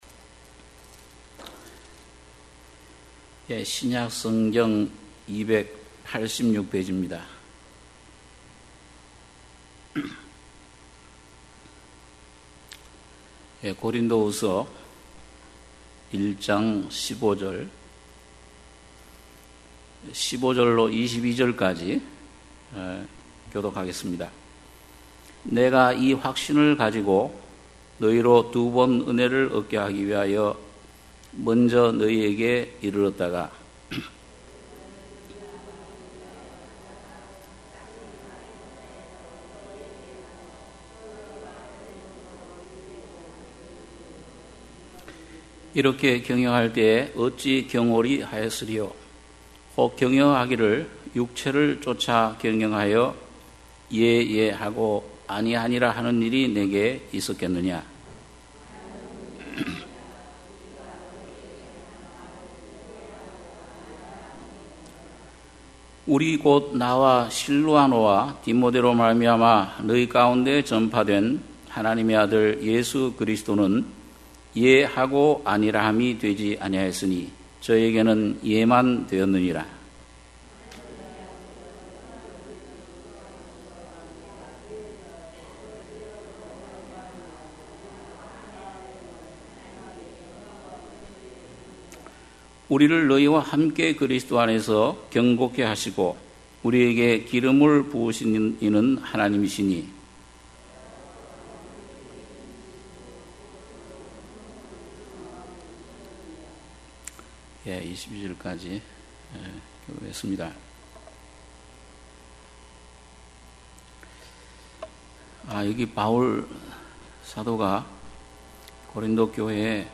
주일예배 - 고린도후서 1장 15-20절(주일오후)